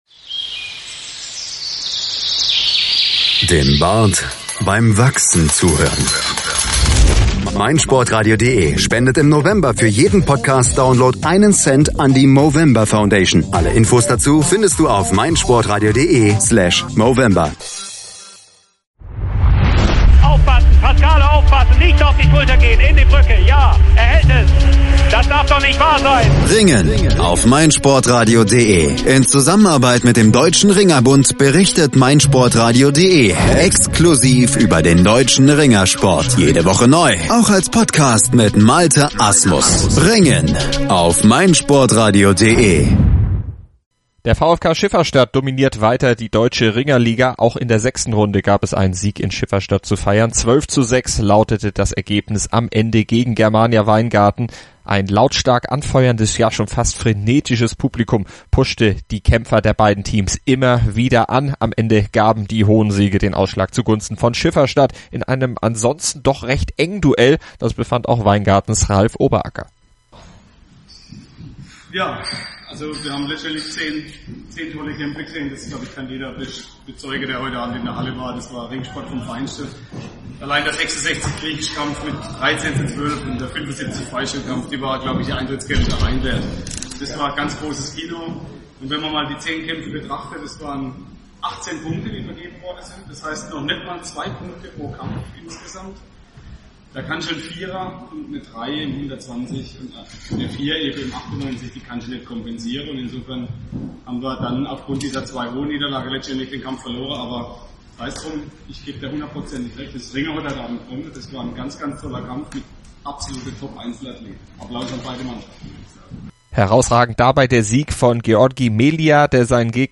Ein lautstark anfeuerndes, fast frenetisches Publikum,